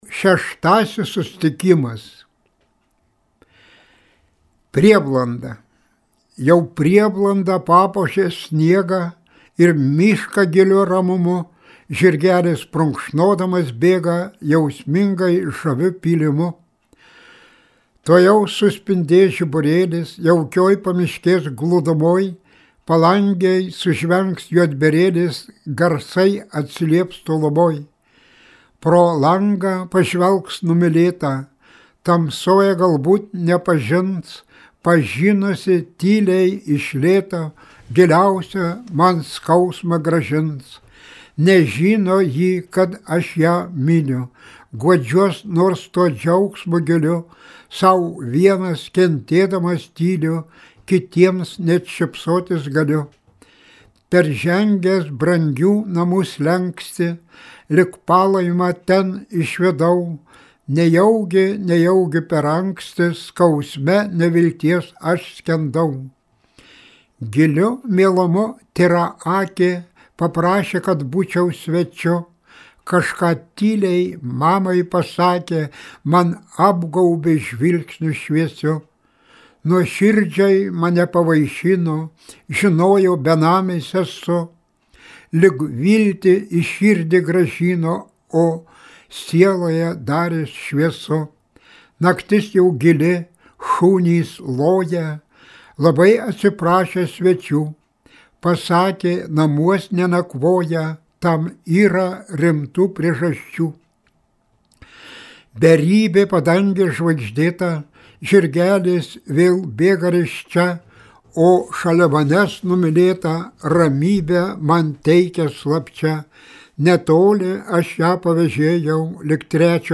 Kviečiame paklausyti legendinio partizano Jono Kadžionio-Bėdos eilių, jo paties skaitomų: